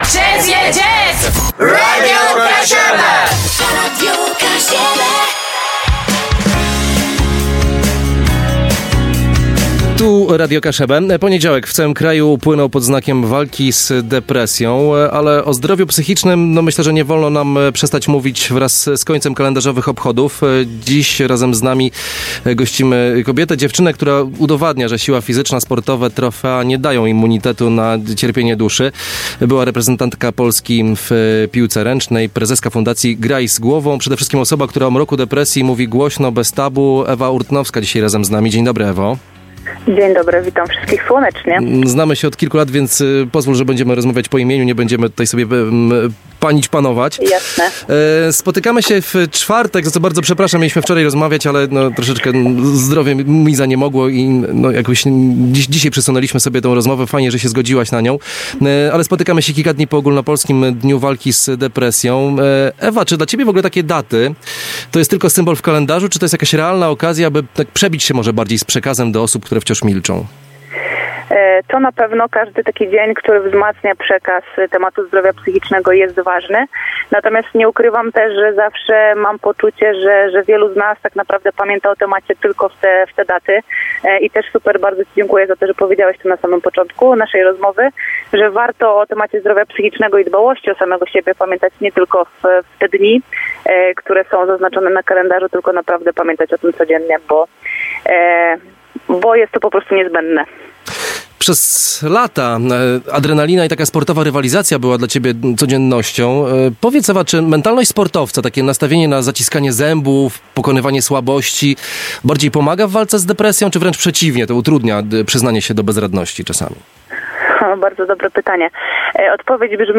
Kluczowym wątkiem wywiadu była analiza psychiki zawodowego sportowca w zderzeniu z chorobą.